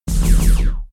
beam.ogg